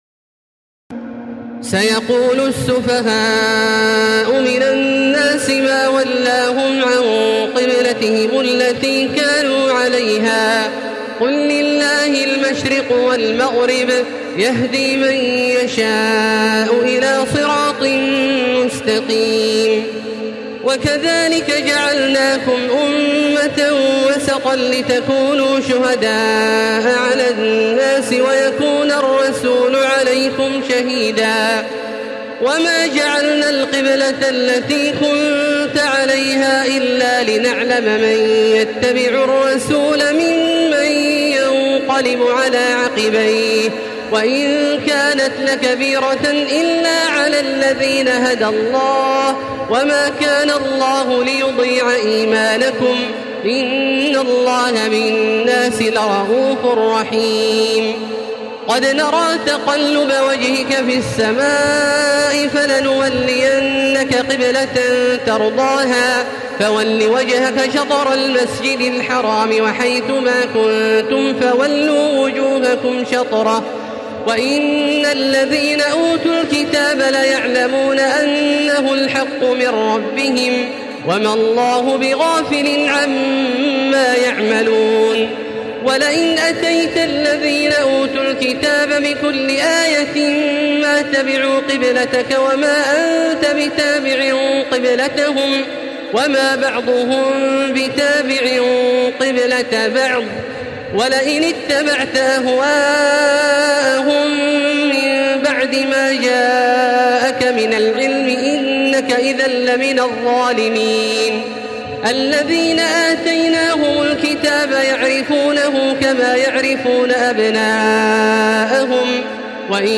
تراويح الليلة الثانية رمضان 1435هـ من سورة البقرة (142-203) Taraweeh 2 st night Ramadan 1435 H from Surah Al-Baqara > تراويح الحرم المكي عام 1435 🕋 > التراويح - تلاوات الحرمين